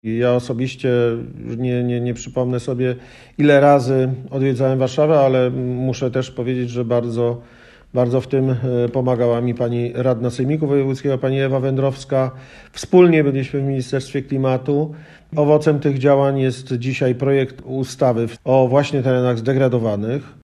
Prezydent Tomaszowa Mazowieckiego Marcin Witko mówi, że kiedy obejmował stanowisko tereny nie były własnością miasta.